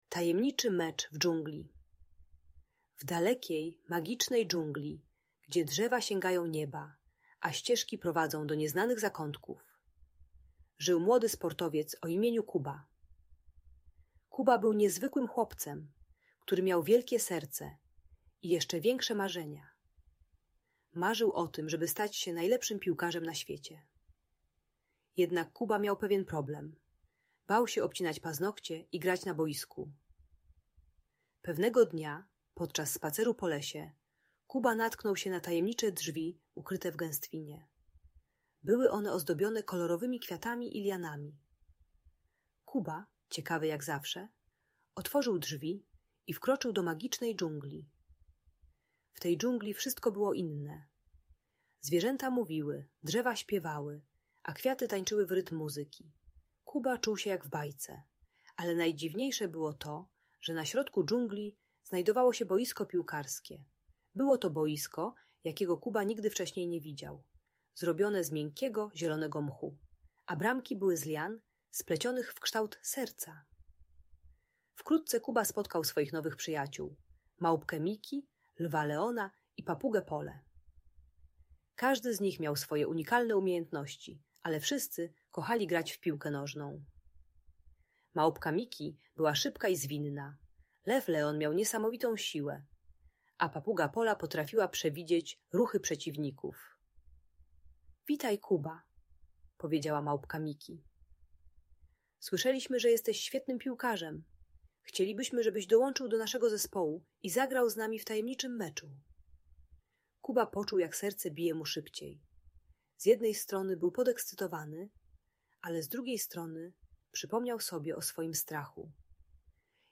Tajemniczy Mecz w Dżungli - Lęk wycofanie | Audiobajka